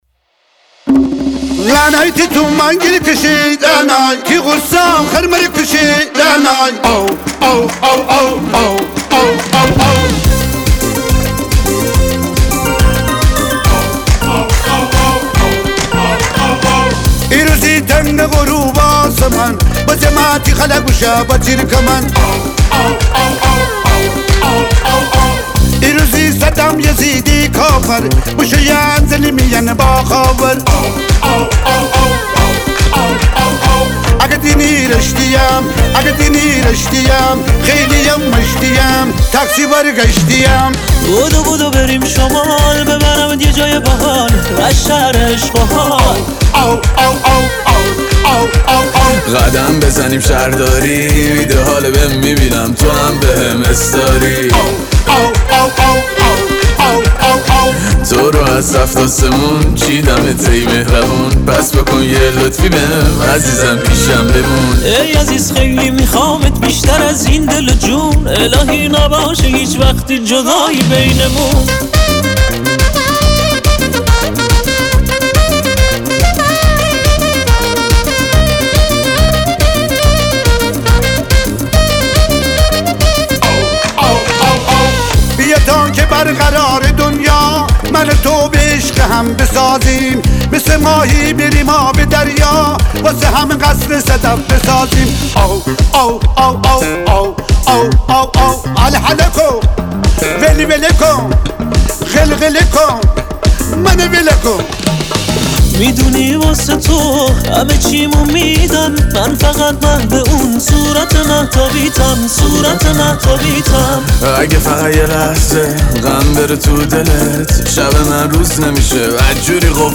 موزیک شاد